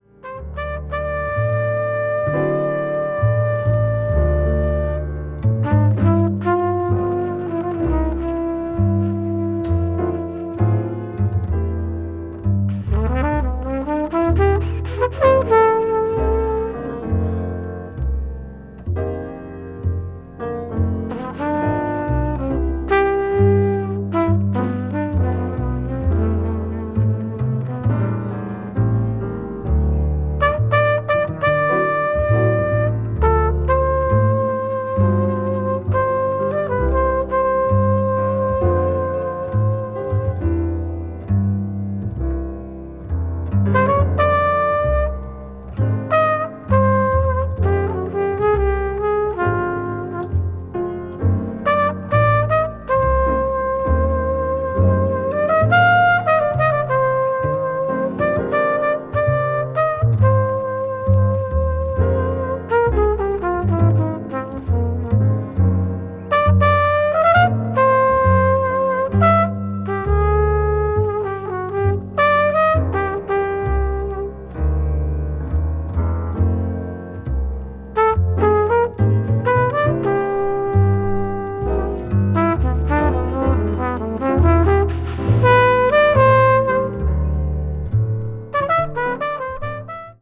柔らかでシンプルなトランペットの旋律
contemporary jazz   jazz standard   modal jazz   modern jazz